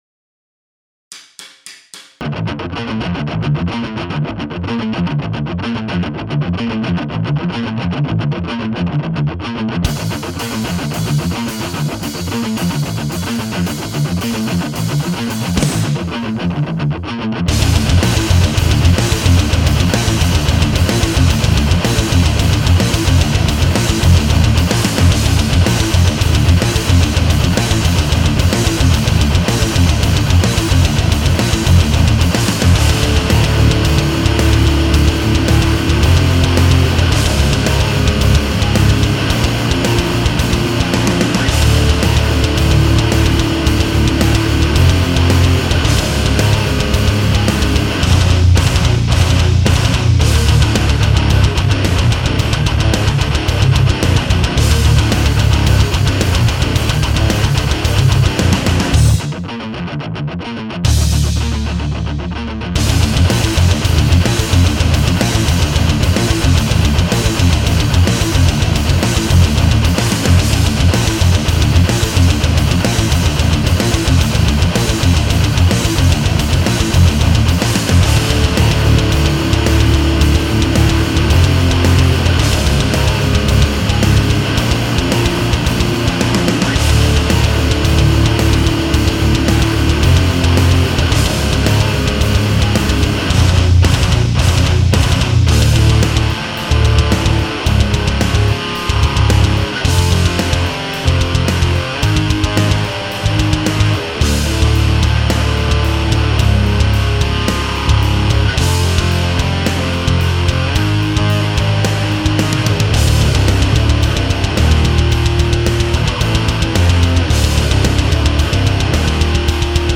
(version premasterisée)
Les grattes sont faites en passant une AMT P1 dans un torpedo live
La basse est passée directement dans le torpedo live
Batterie SD 2.0 (kit Avatar de base)
morceau 1 : super son de gratte/super jeu/super riffs ! La basse a l'air cool aussi, mais a besoin de plus de compression (bas qui saute trop) et aussi si c'est pas déjà fait d'une piste mids pleine de disto pour lier un peu avec les guitares.
La batterie est trop faible en volume dans ton mix.